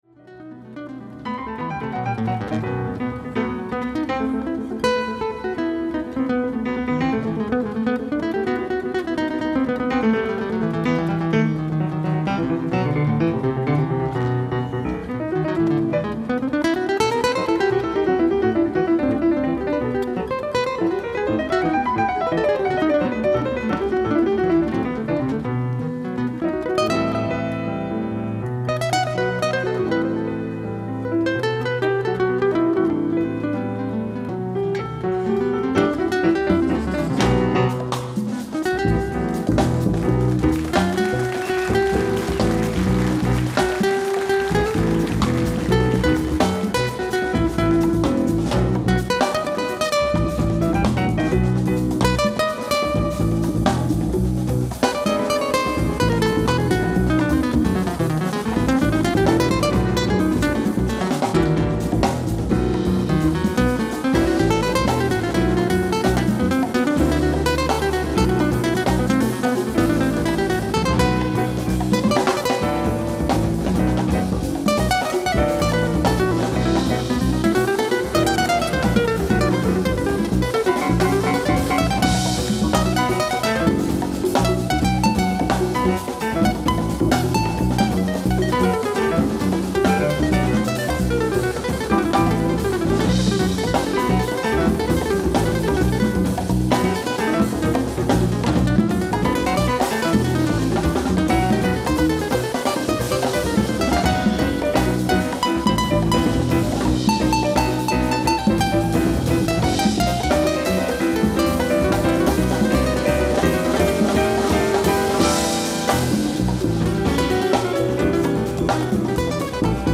ライブ・アット・ニース・ジャズフェスティバル、ニース、フランス 07/11/2013